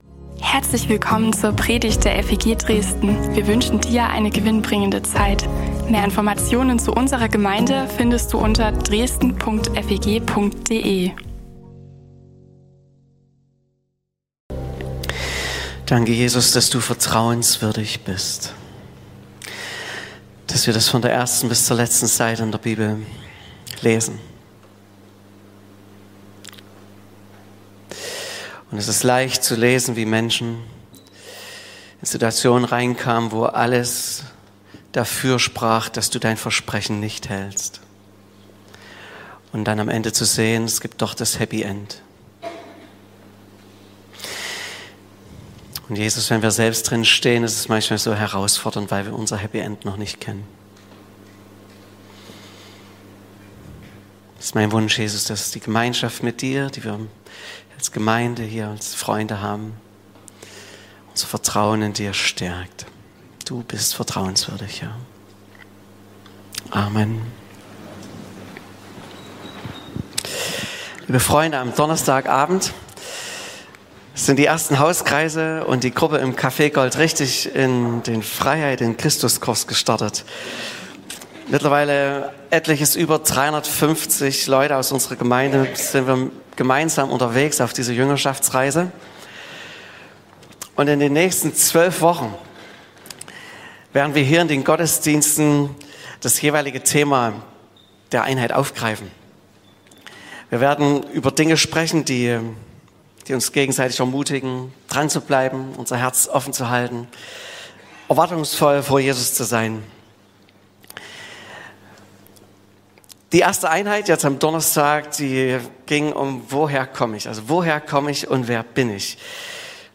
Predigten und mehr